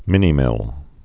(mĭnē-mĭl)